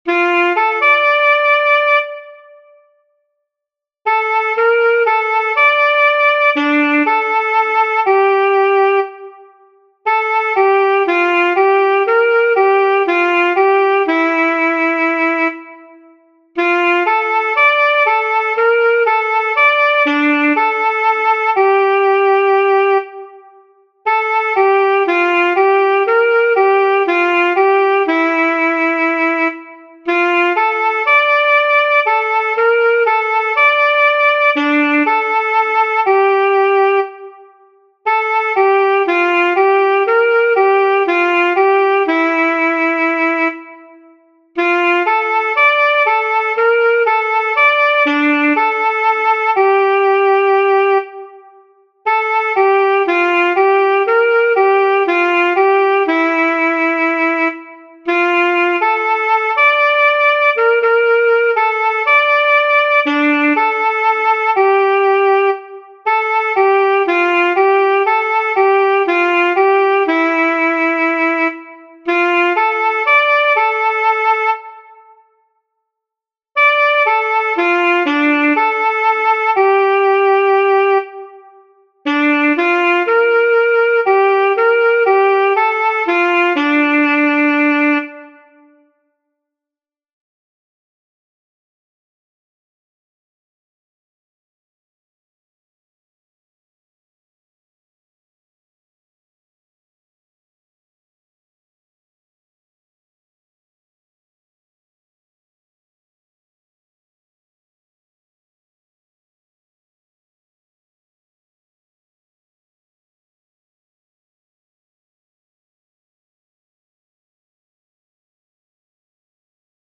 • Аранжировка: Нет
• Жанр: Авторская песня